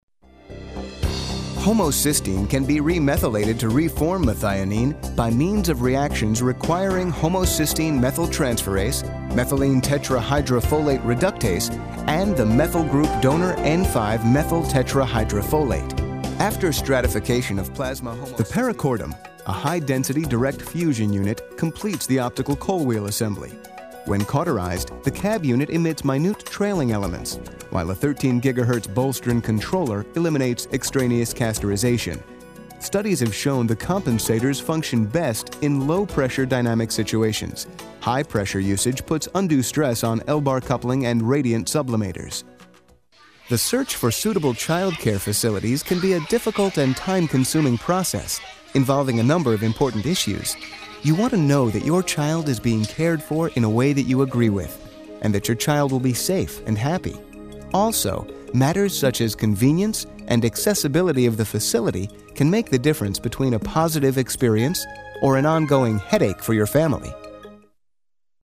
THE SOUND OF SOMEONE YOU KNOW...AND SOMEONE YOU TRUST When you need a guy who sounds like a guy, I’m your guy.